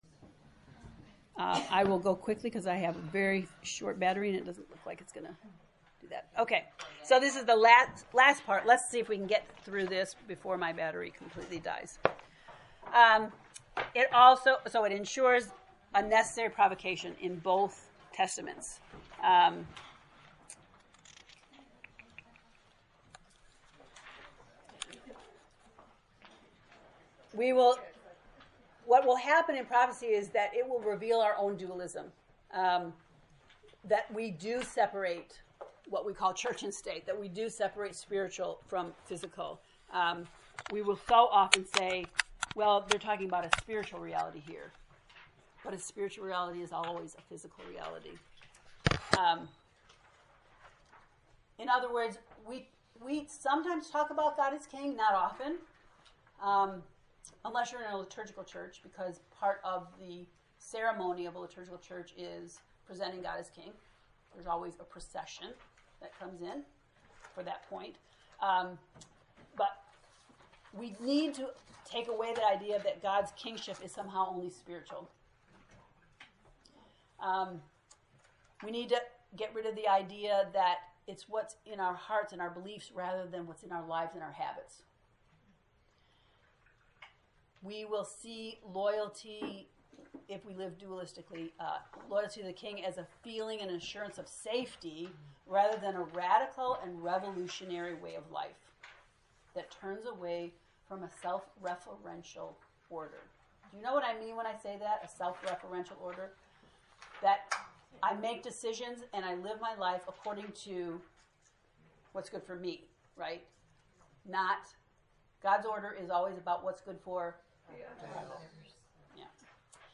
To listen to lesson 9 lecture (in 2 parts), “Introduction,” click below: